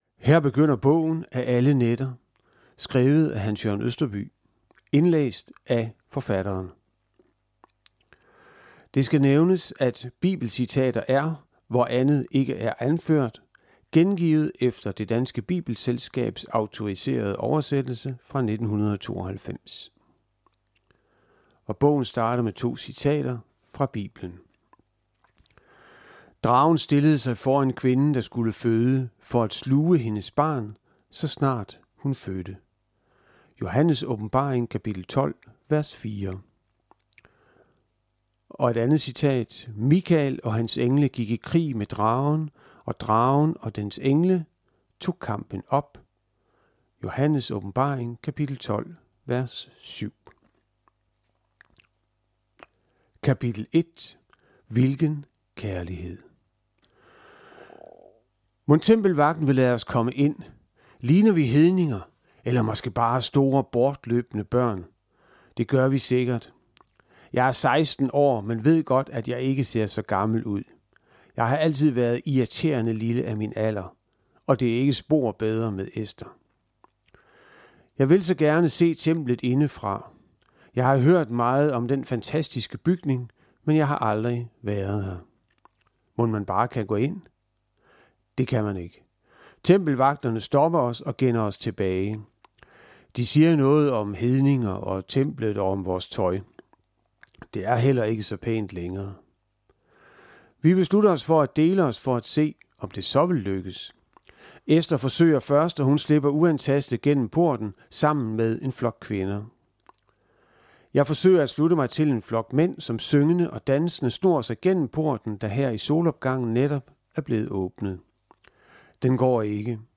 Hør et uddrag af Af alle nætter Af alle nætter Format MP3 Forfatter Hans Jørn Østerby Bog Lydbog 149,95 kr.